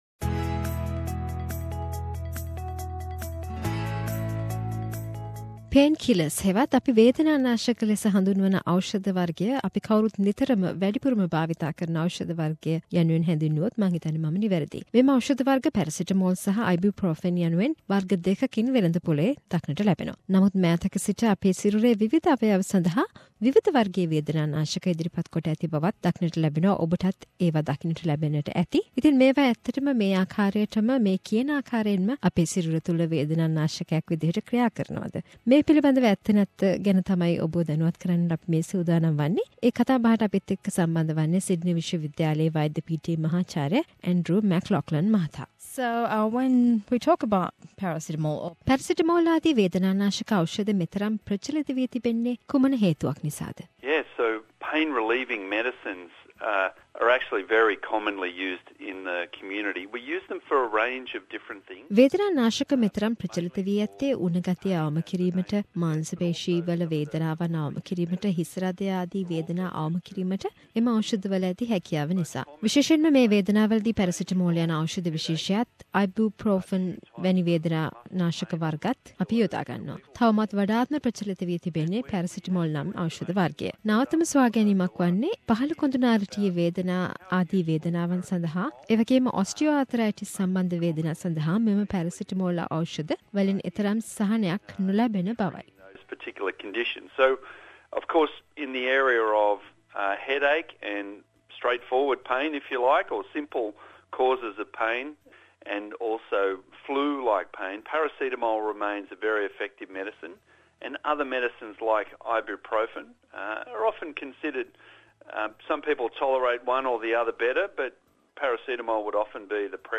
A discussion on pain killers